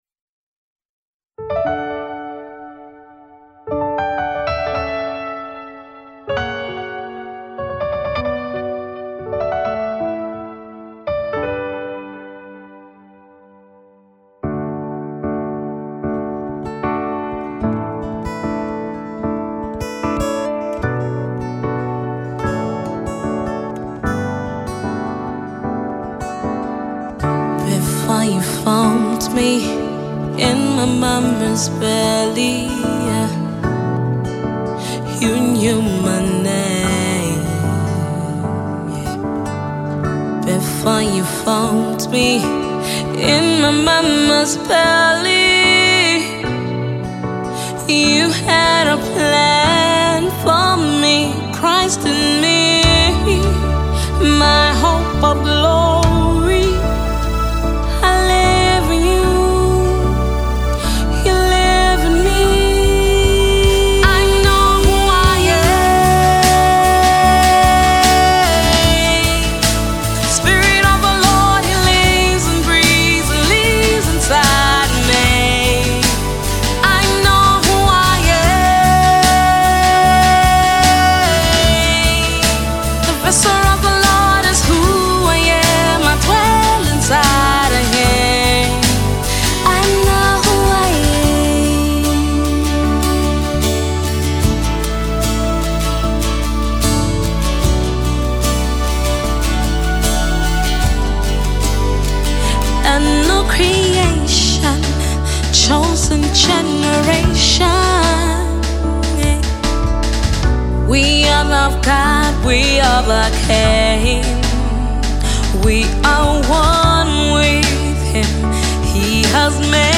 a praise and worship melody